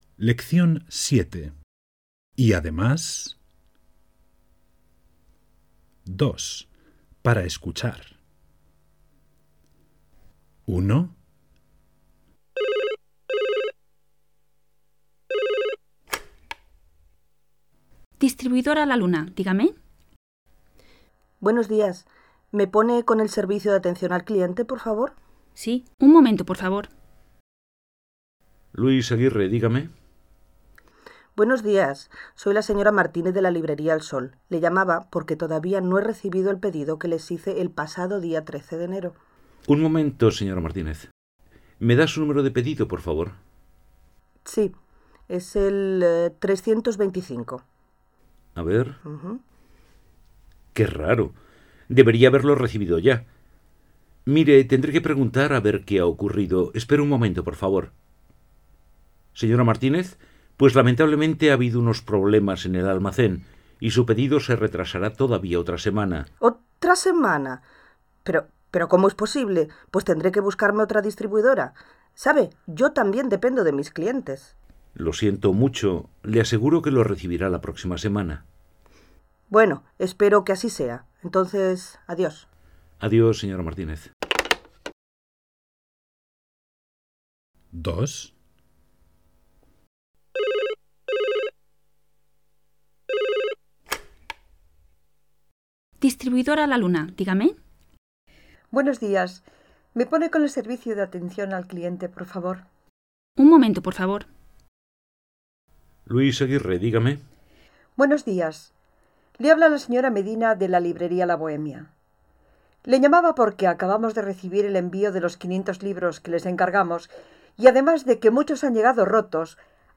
Escuche estas tres conversaciones teléfónicas. Tres clientes de la distribuidora “La Luna“ llaman para reclamar.
Sie hören die drei Telefongespräche.